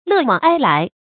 乐往哀来 lè wǎng āi lái 成语解释 欢乐逝去，悲哀到来。